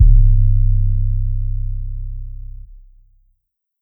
See You Again 808.wav